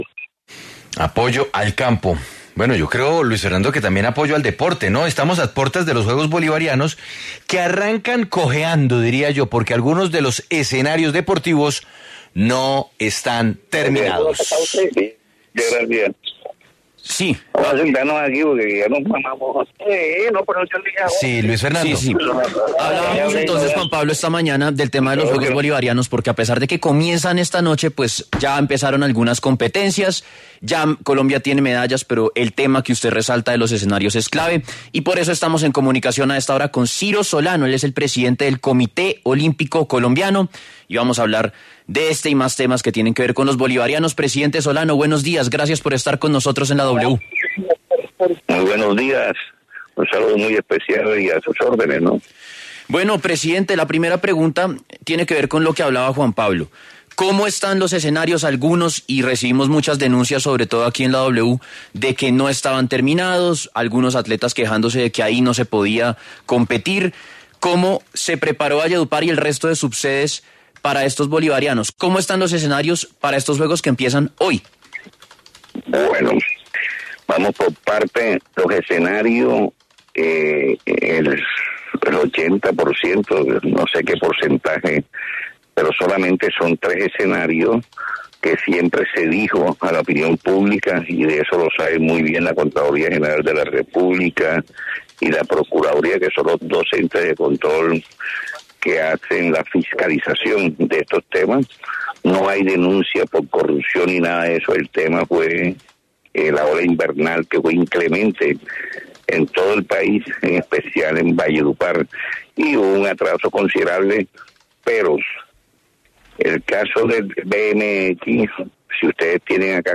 Ciro Solano, presidente del Comité Olímpico Colombiano, se pronunció en La W sobre el inicio de los Juegos Bolivarianos que arrancan este viernes 24 de junio.